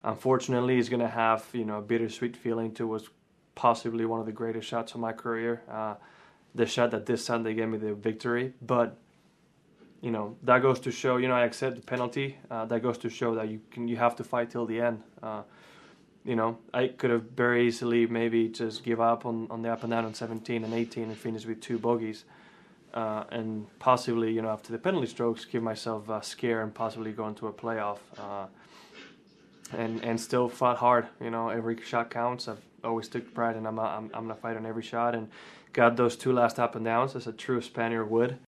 After the tournament, Rahm commented on whether he knew the ball moved.